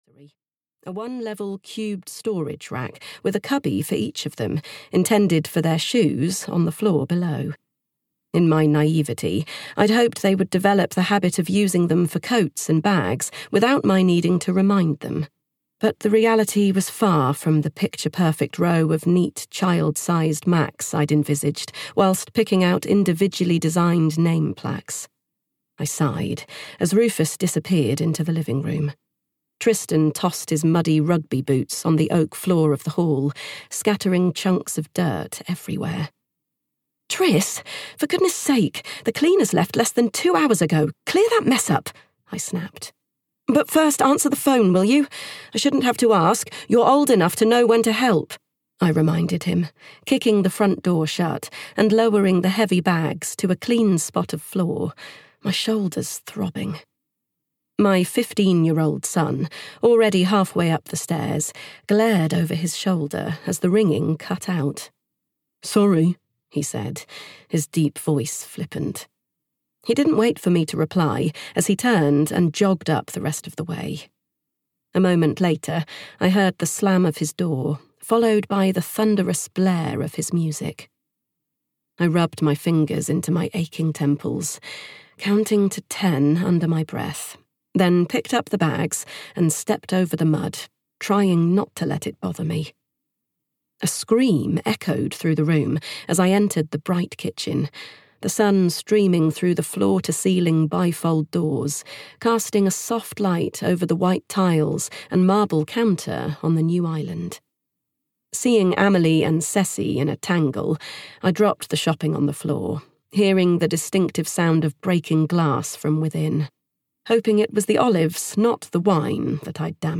Audio knihaHer Silent Husband (EN)
Ukázka z knihy